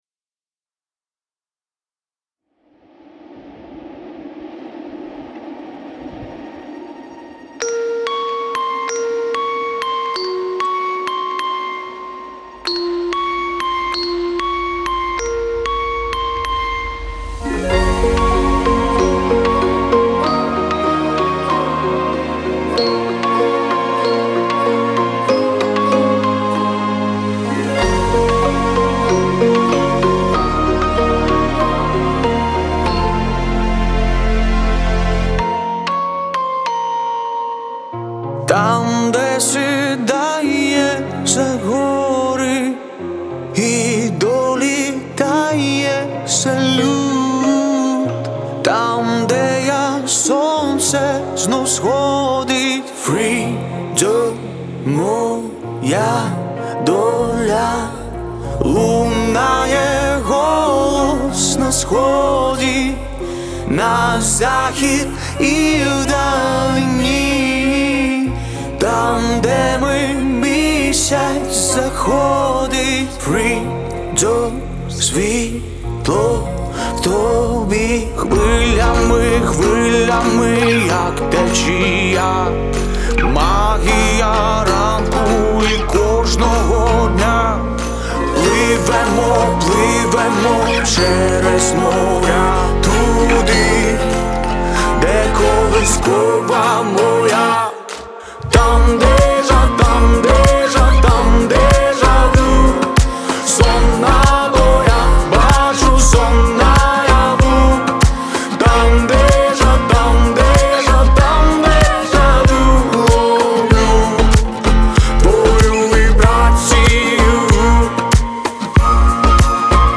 это трек в жанре акустической поп-музыки